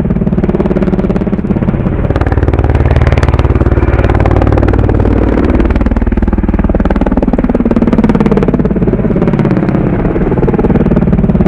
helicopter.ogg